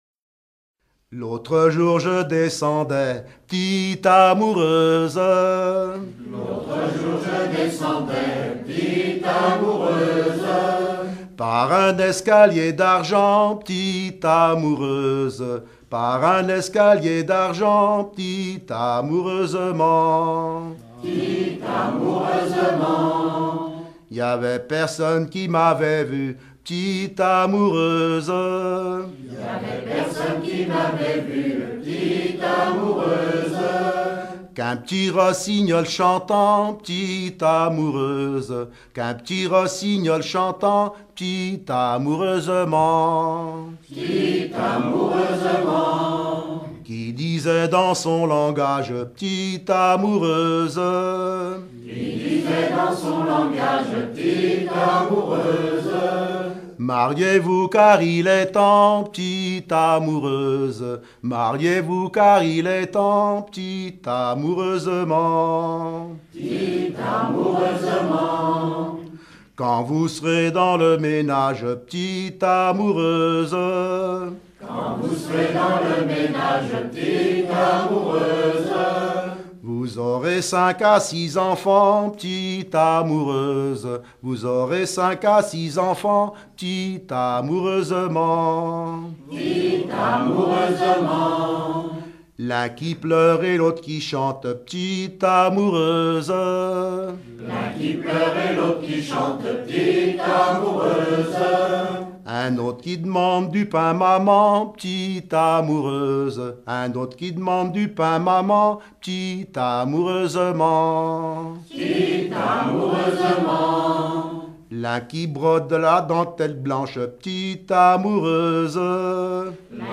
Chansons en dansant